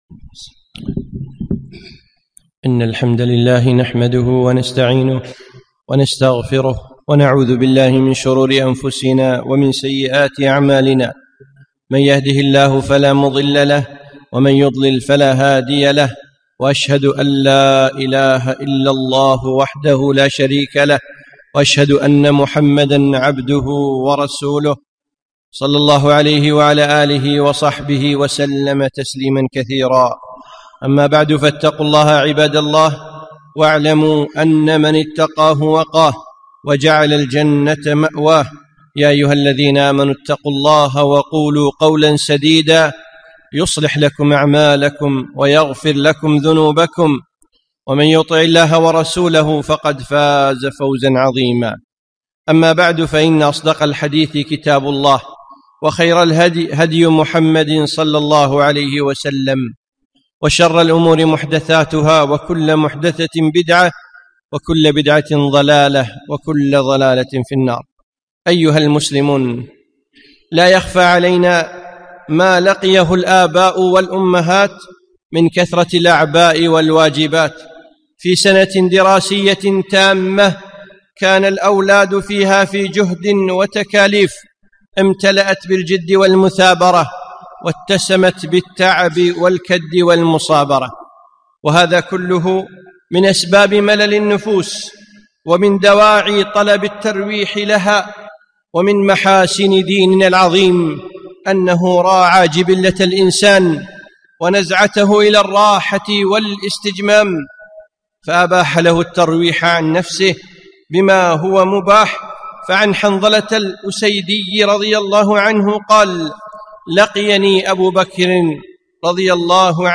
خطبة - الإجازة الصيفية